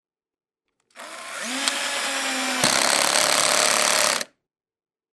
Звуки шуруповерта
Закручиваем шуруп в бетонную стену